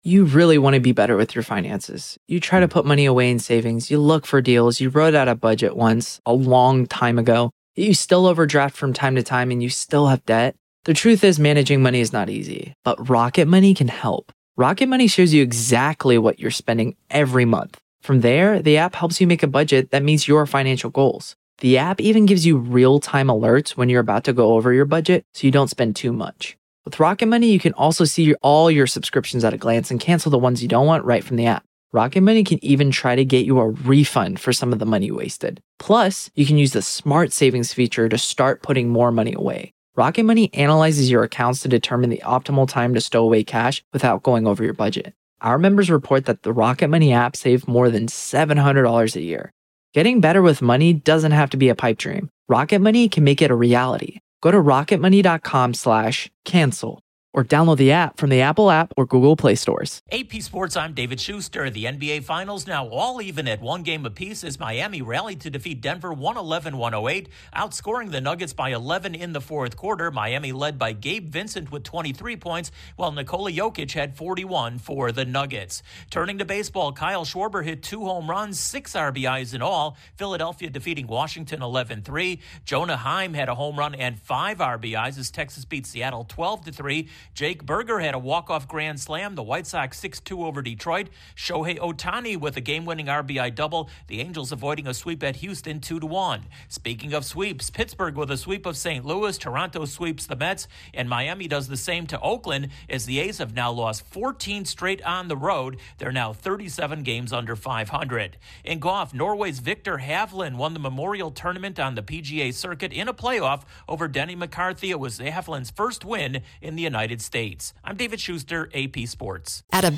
Sports News from the Associated Press / The latest in sports